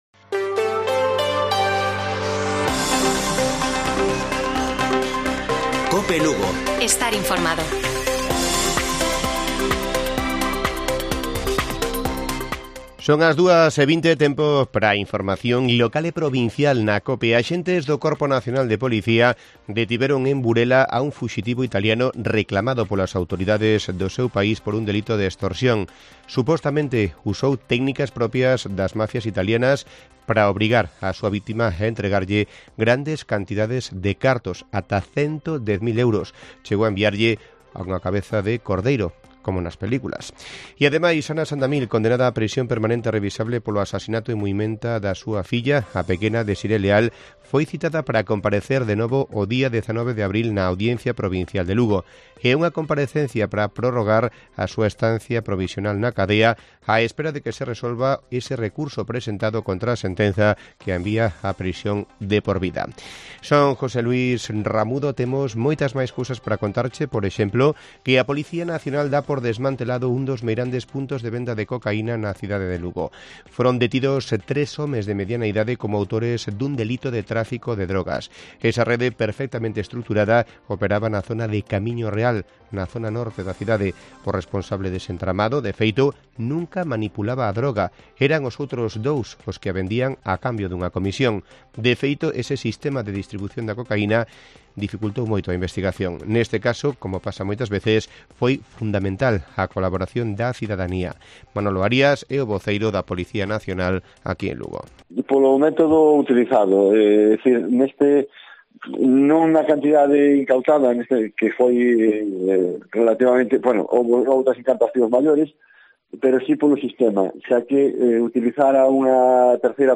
Informativo Mediodía de Cope Lugo. 13 de marzo. 14:20 horas